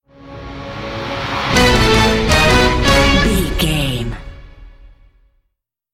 Ionian/Major
C#
horns
drums
electric guitar
synthesiser
orchestral
orchestral hybrid
dubstep
aggressive
energetic
intense
strings
bass
synth effects
wobbles
heroic
driving drum beat
epic